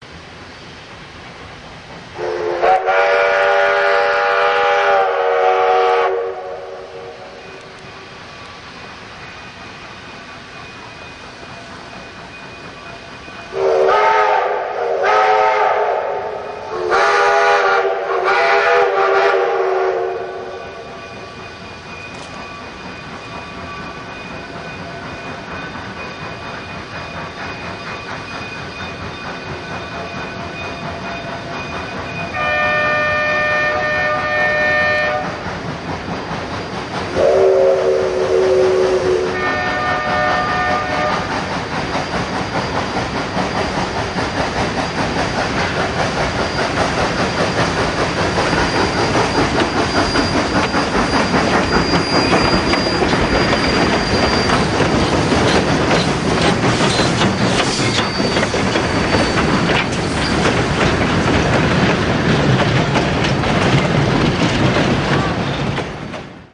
steam loco